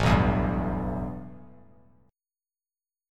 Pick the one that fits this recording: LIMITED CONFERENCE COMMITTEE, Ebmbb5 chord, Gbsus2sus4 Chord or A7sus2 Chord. A7sus2 Chord